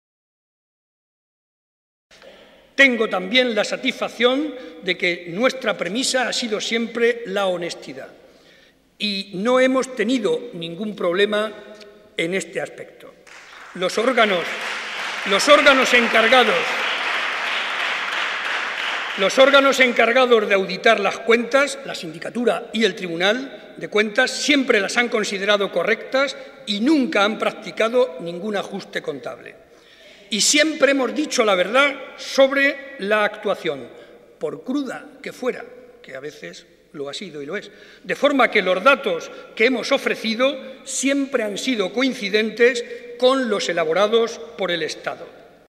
Cortes de audio de la rueda de prensa
audio_Barreda_Discurso_Debate_Investidura_210611_4